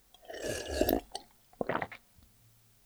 DRINK_Long_mono.wav